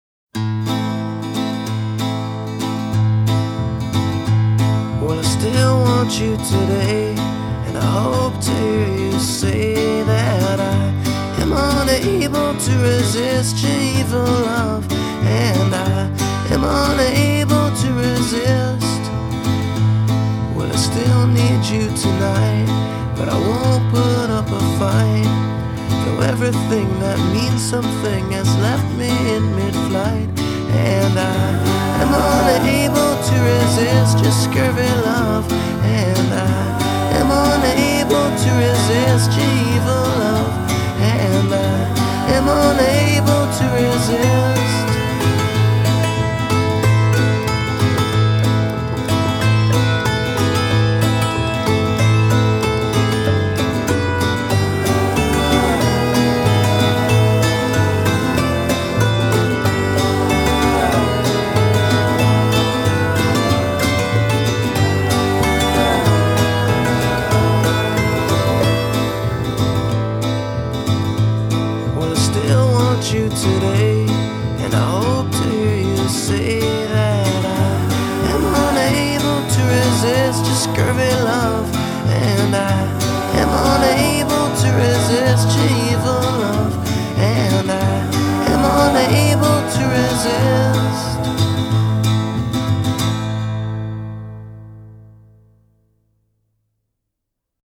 power pop